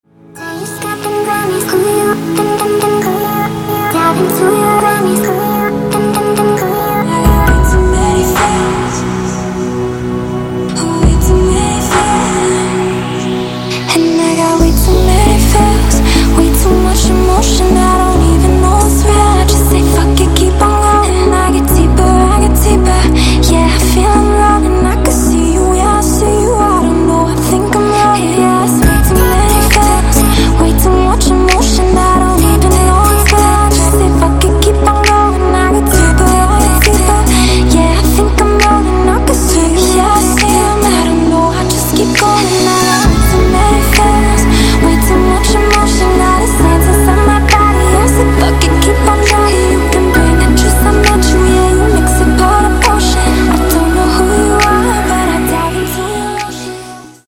• Качество: 128, Stereo
женский вокал
Electronic
Chill
vocal